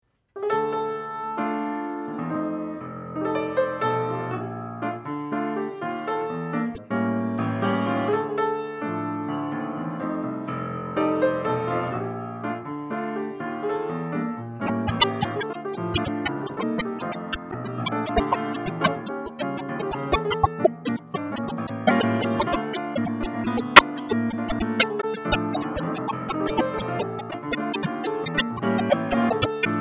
Jazz
Easy Listening
Cocktail Music
Piano Jazz , Solo Piano